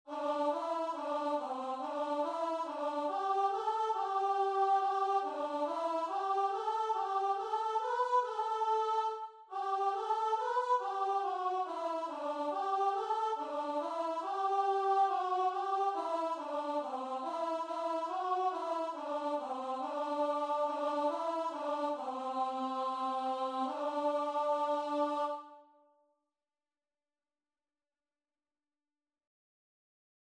Traditional Music of unknown author.
4/4 (View more 4/4 Music)
G major (Sounding Pitch) (View more G major Music for Voice )
Instrument:
Voice  (View more Easy Voice Music)
Christian (View more Christian Voice Music)